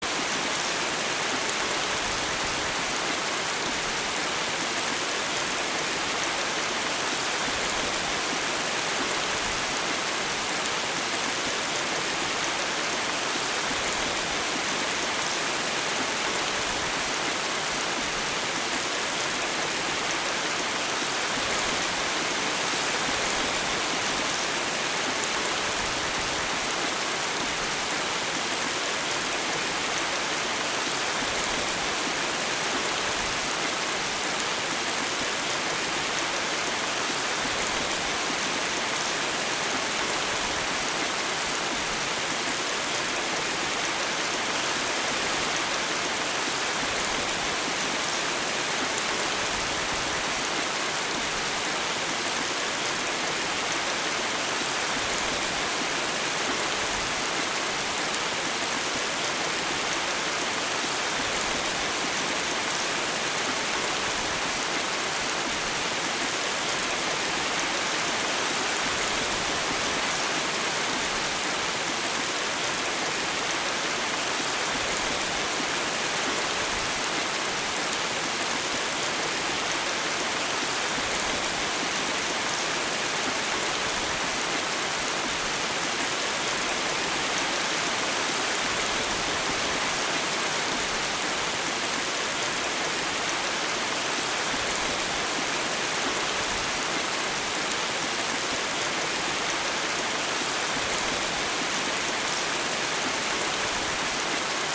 Шум и звук дождя без грома